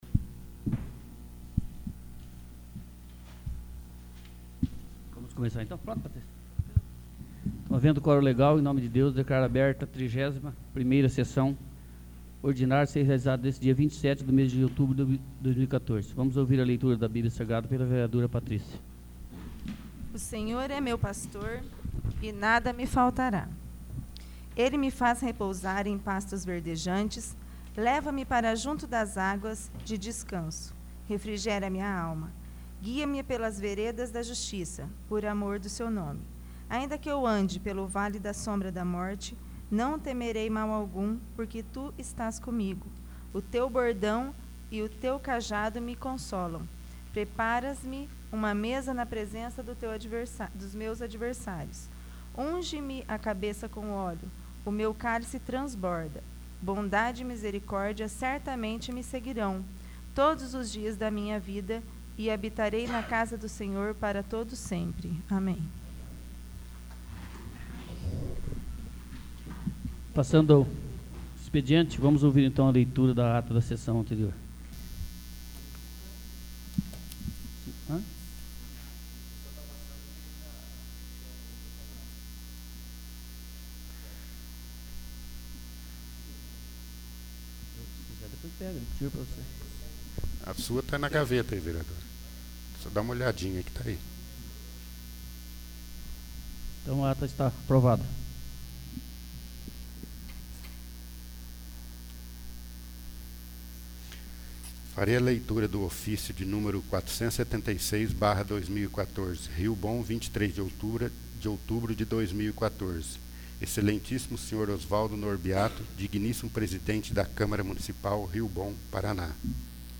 31º. Sessão Ordinária